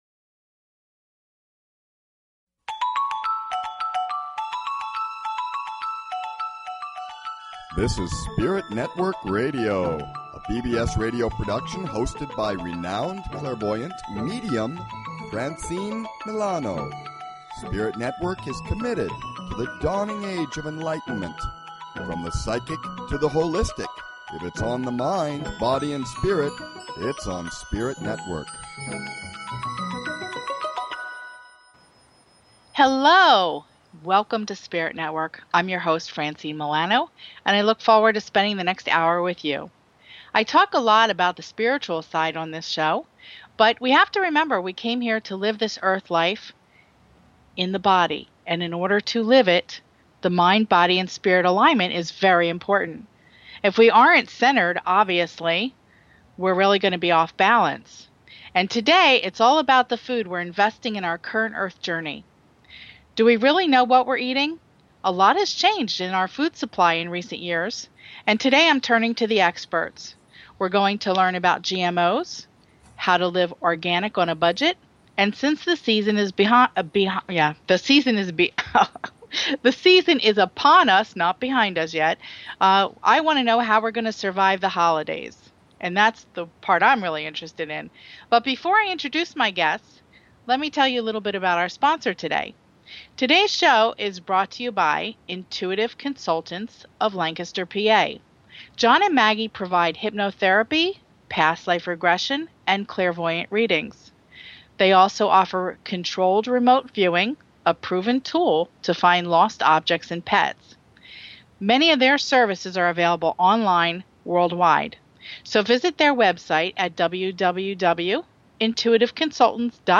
Talk Show Episode, Audio Podcast, GMOs.
with the Health by Design experts